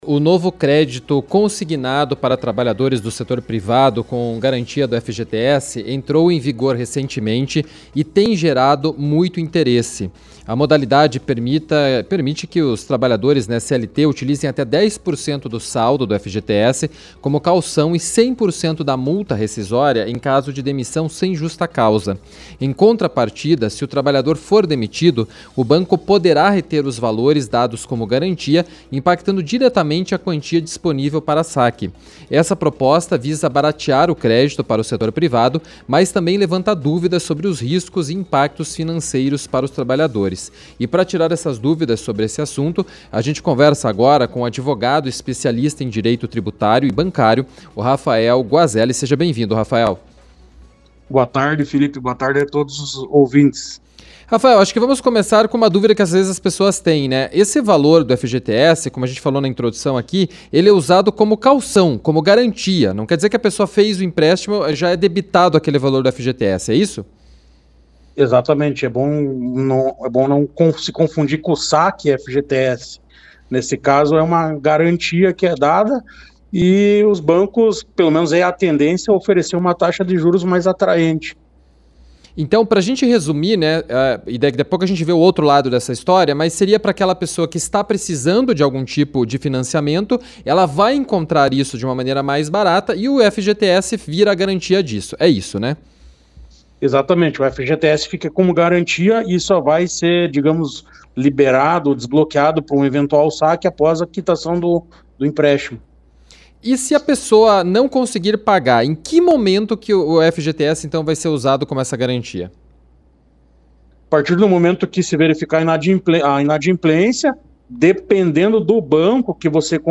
conversou com o advogado especialista em Direito Bancário e Tributário